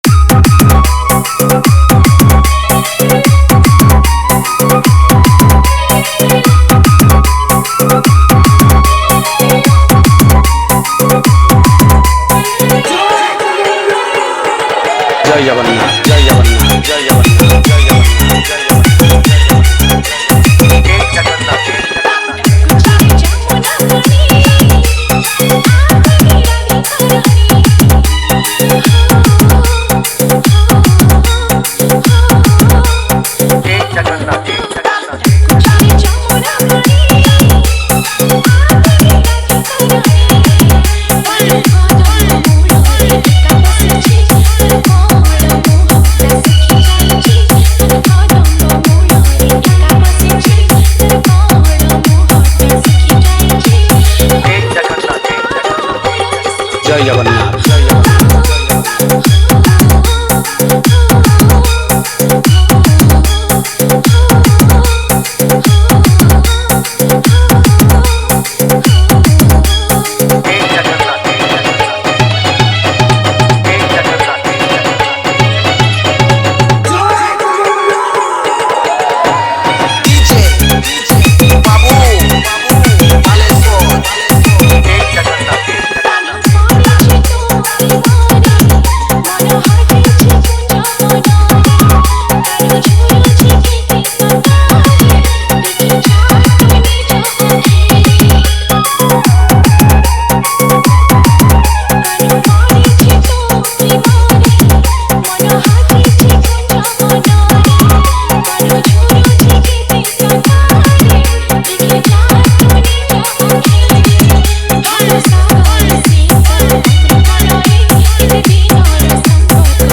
Category:  Odia Bhajan Dj 2022